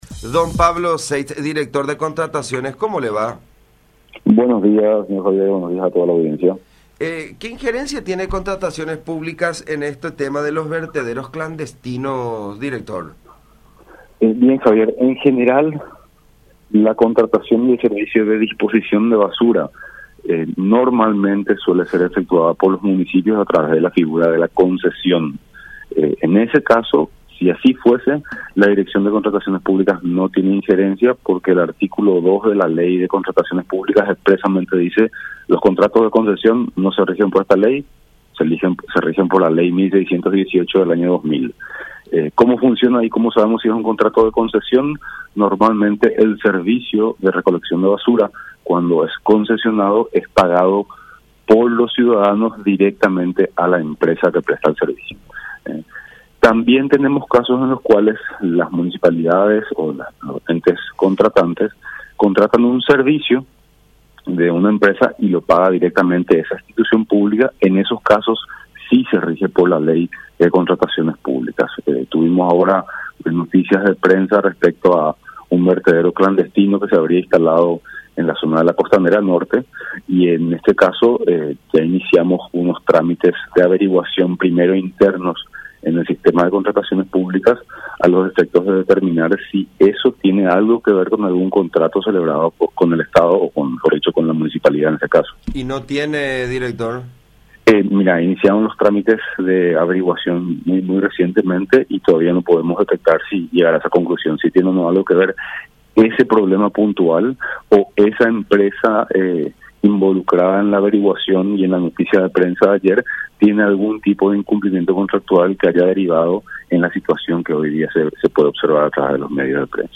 “Estamos en etapa de averiguaciones sobre cómo se dieron estas situaciones, pero ya tenemos información de algunos vertederos clandestinos en la zona de la Costanera”, dijo Pablo Seitz, director de DNCP, en contacto con La Unión.
04-Pablo-Seitz-Director-de-Contrataciones-Públicas.mp3